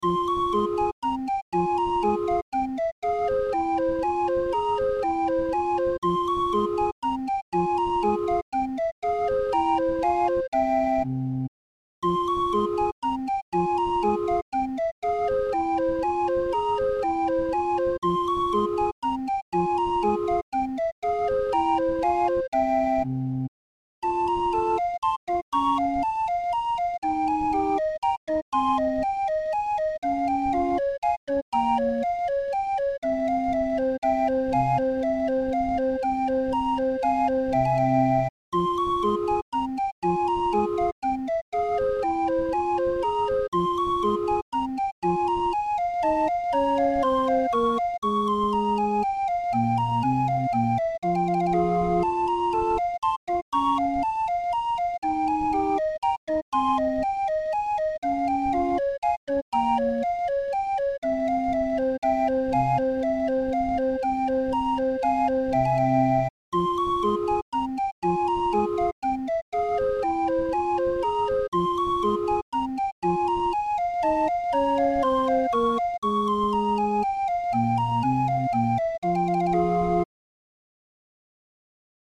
Musikrolle 31-er